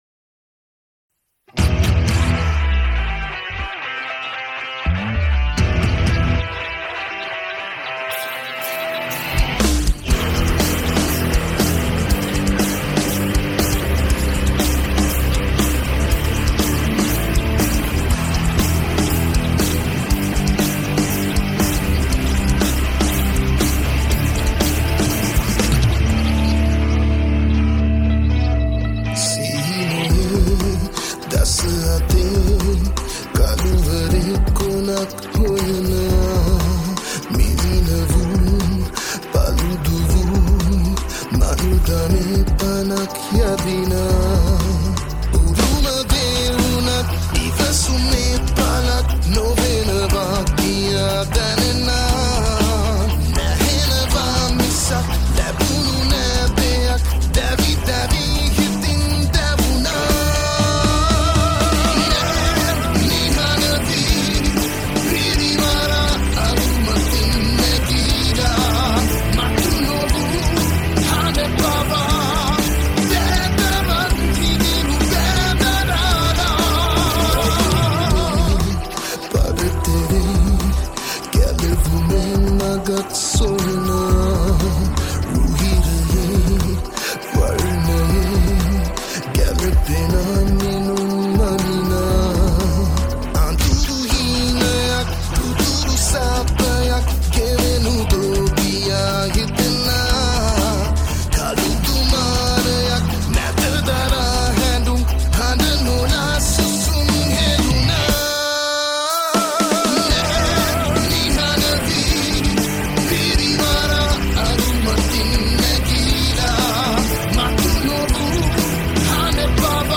Guitars
Bass
Drums